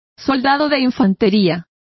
Complete with pronunciation of the translation of infantryman.